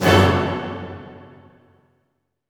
Index of /90_sSampleCDs/Roland L-CD702/VOL-1/HIT_Dynamic Orch/HIT_Orch Hit min
HIT ORCHM09R.wav